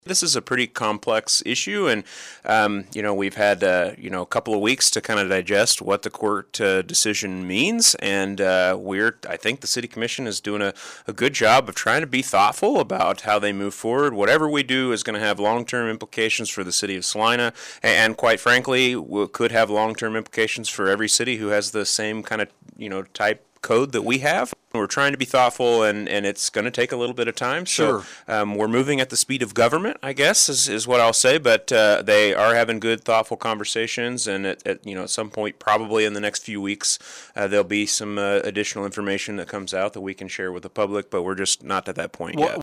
Salina City Manager Jacob Wood joined in on the KSAL Morning News Extra on Tuesday with a report card on the process.